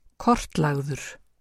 framburður
kort-lagður